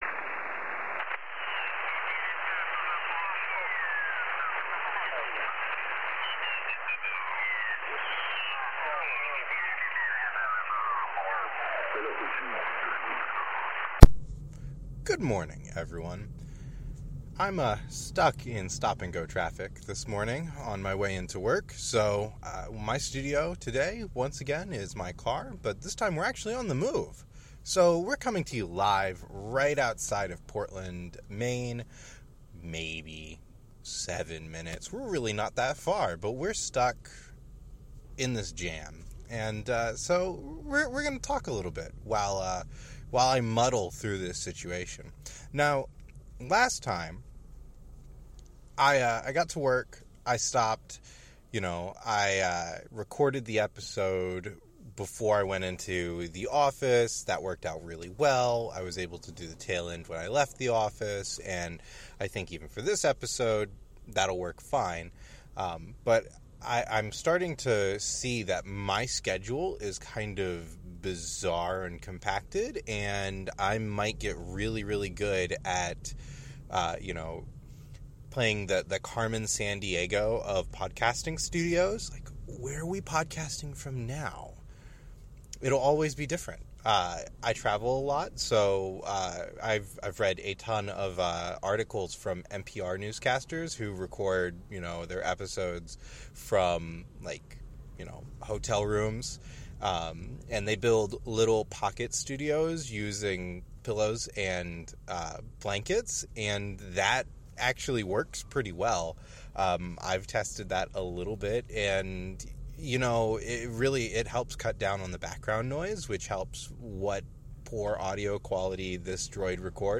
I got stuck in traffic and decided that was the perfect opportunity to record the next episode of LTRG. I've been fiddling with my audio setup in the interim and am pleased to say that the audio quality should be worlds better than the first episode. Probably not perfect, but you can listen to it without straining your ears finally!